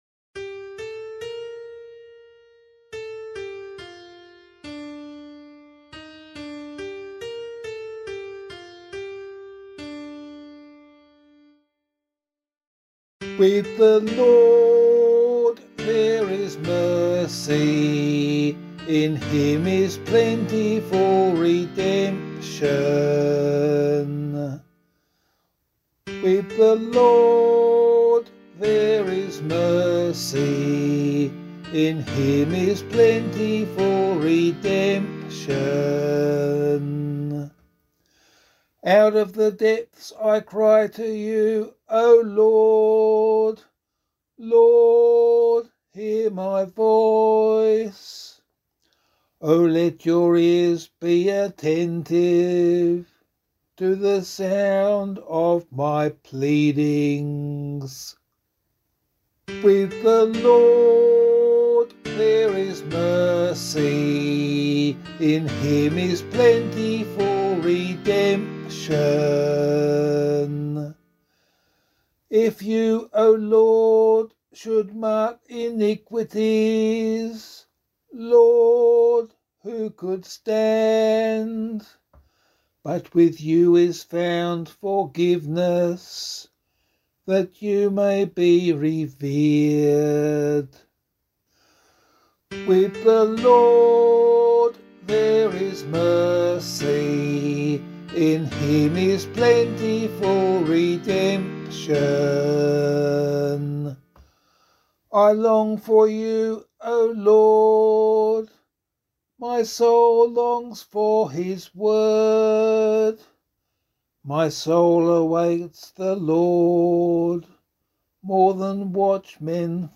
017 Lent 5 Psalm A [APC - LiturgyShare + Meinrad 3] - vocal.mp3